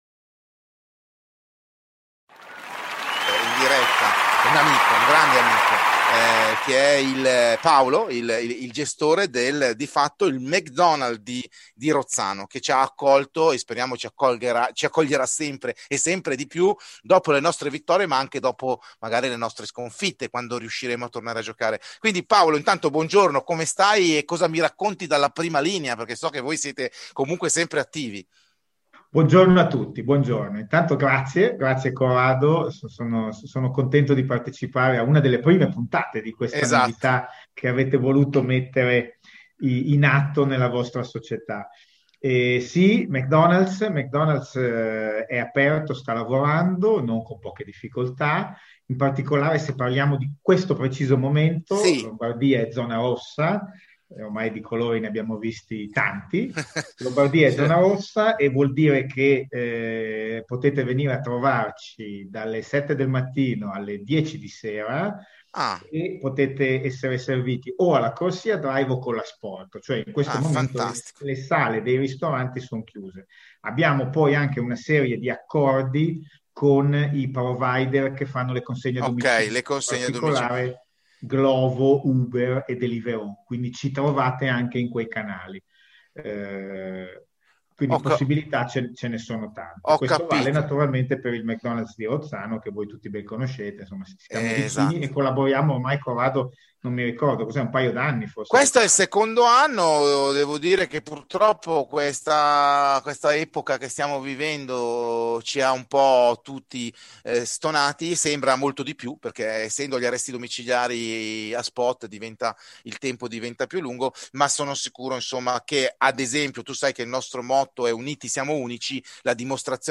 Terzo episodio, intervista inedita al nostro sponsor.